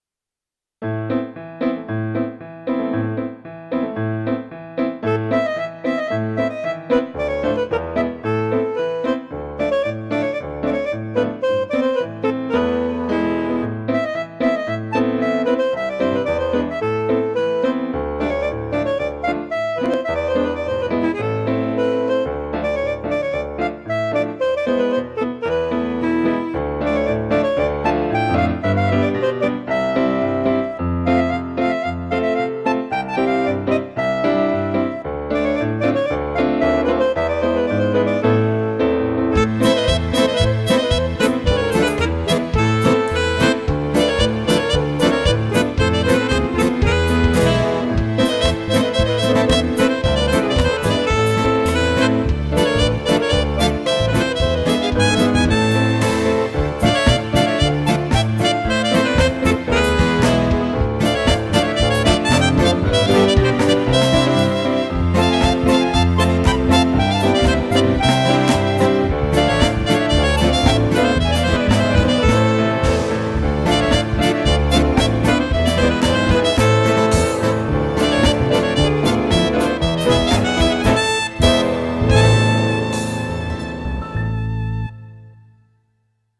ריקוד כליזמר